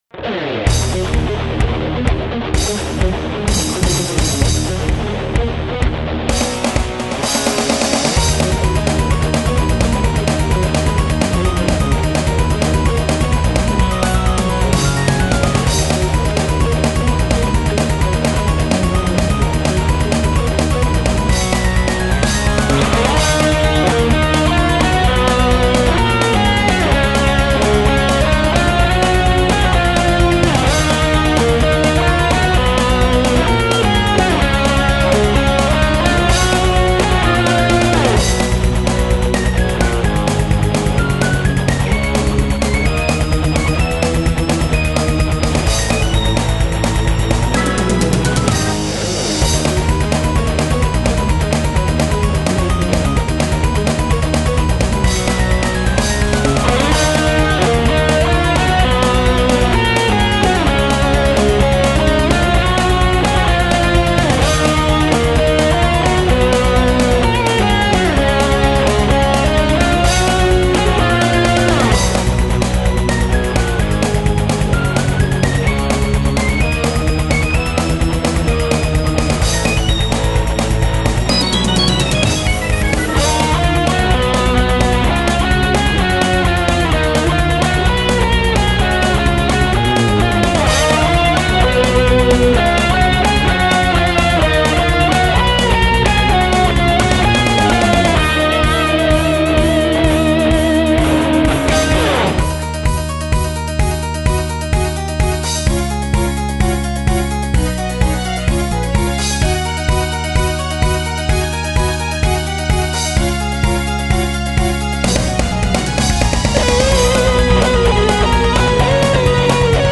生まれて初めて銀歯を入れられた日に完成したインスト曲。
良いメロだったのでクドイくらい繰り返している。
リフはパンチインなしに拘って録音した。